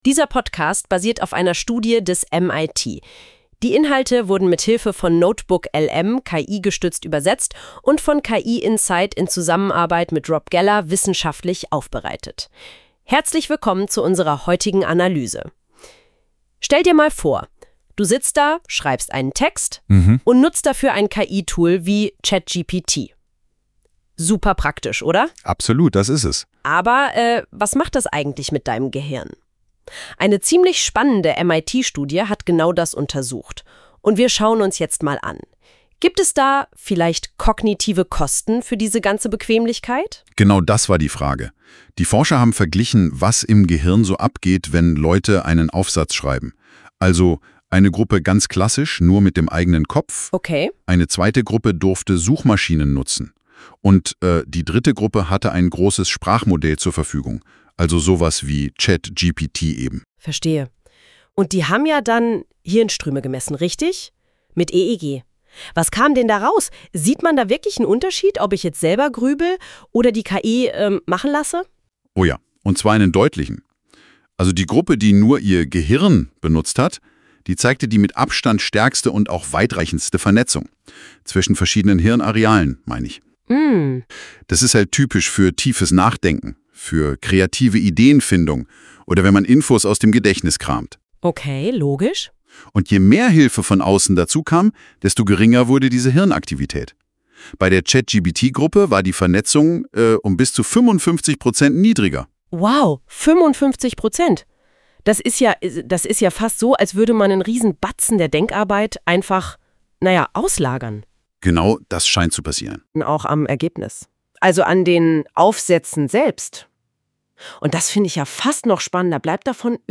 Das Besondere an diesem Podcast, er wird zu 100 % mithilfe von KI vollautomatisiert erstellt – von d